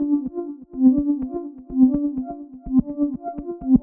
cch_synther_125_F#m.wav